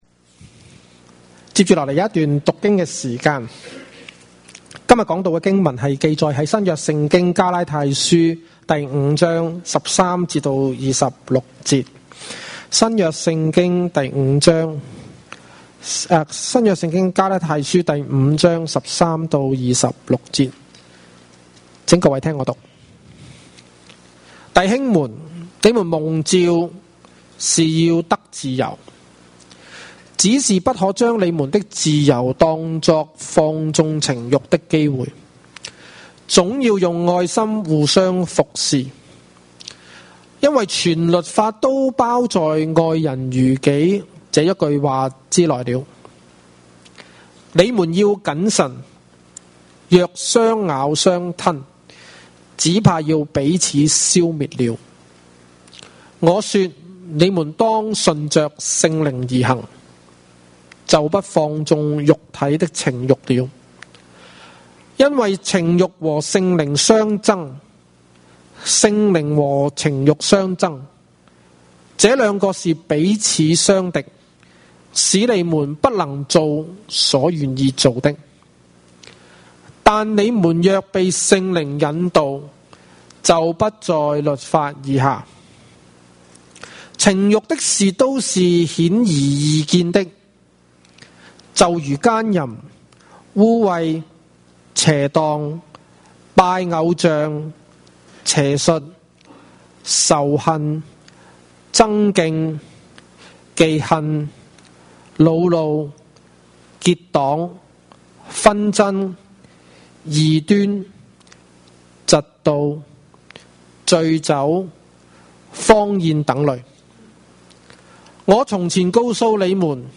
Cantonese 3rd Service, Chinese Category